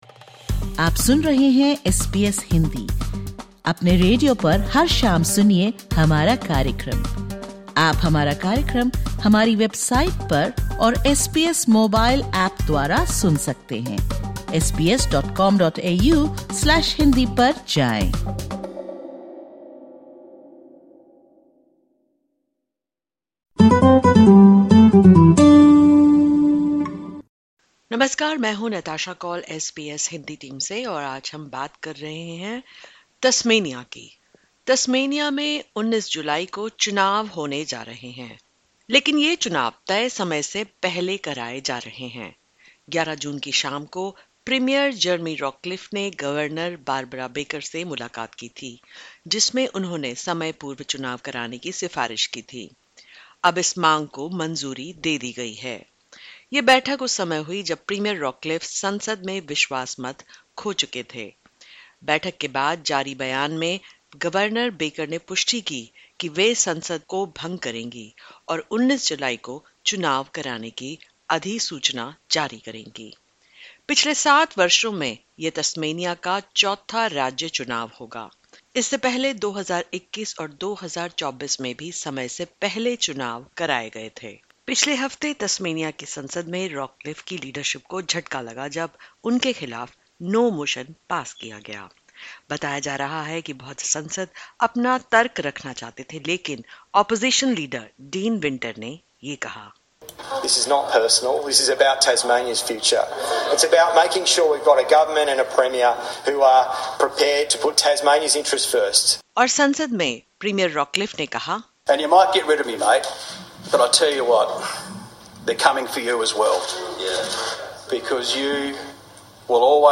Disclaimer: The views expressed in this podcast episode are those of the interviewee and do not reflect the views of SBS Hindi.